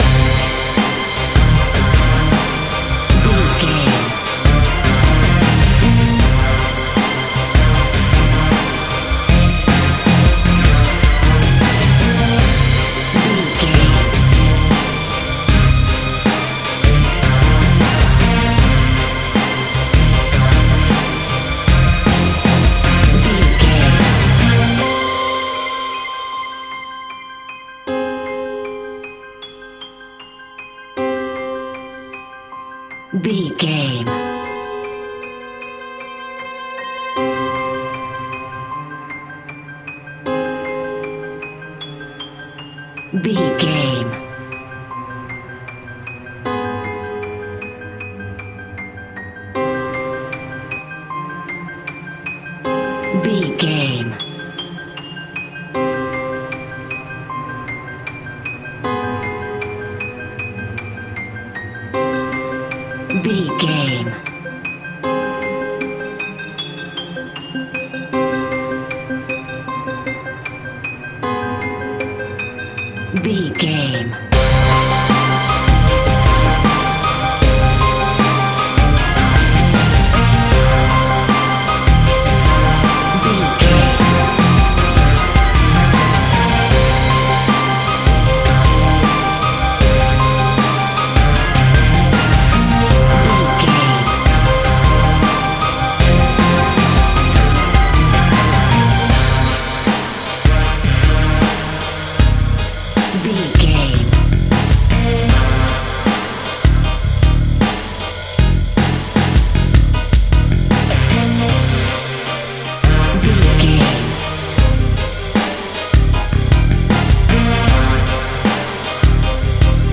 Aeolian/Minor
Fast
ominous
dark
suspense
eerie
violin
piano
drums
strings
cello
electric guitar
viola
orchestral instruments